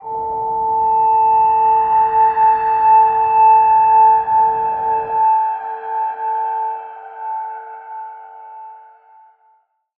G_Crystal-A5-f.wav